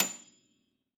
53f-pno29-A6.aif